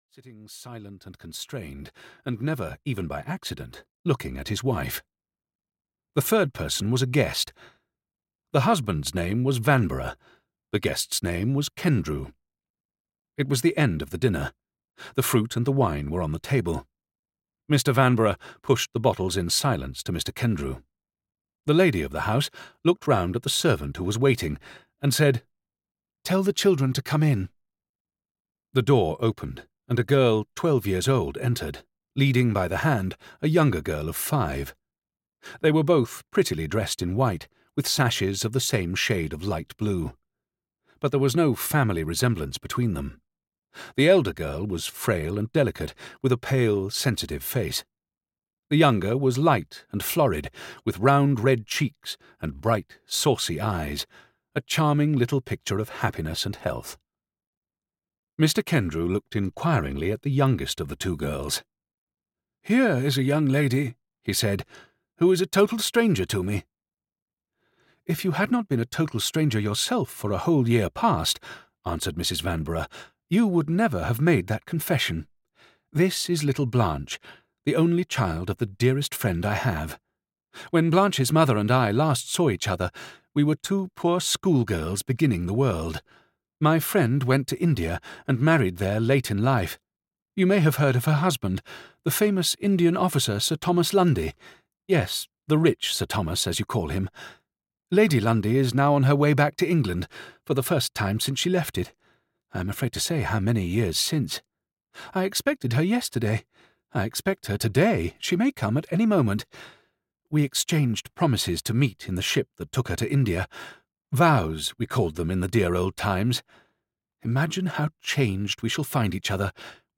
Man and Wife (EN) audiokniha
Ukázka z knihy